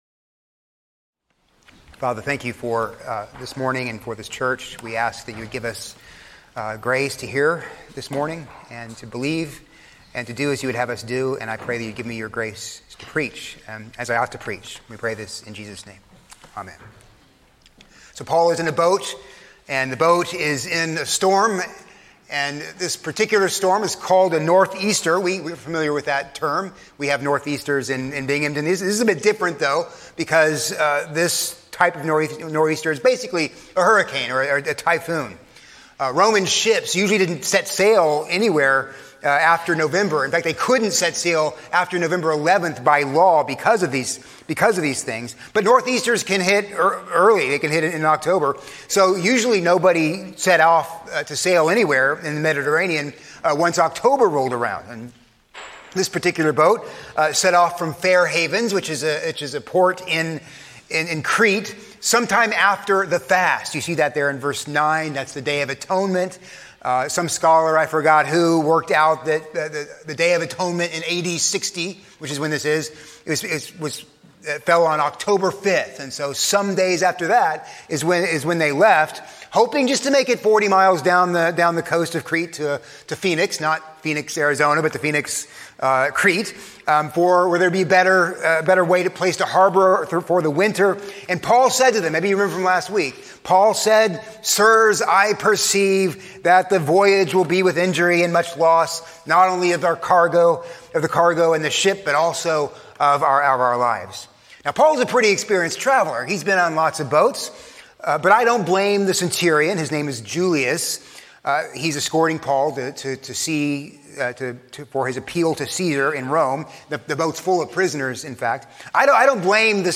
A sermon on Acts 27:27-44